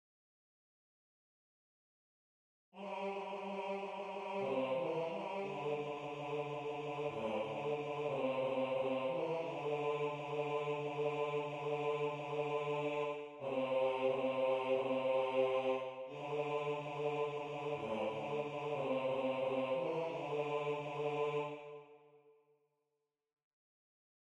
MP3 rendu voix synth.
Basse 2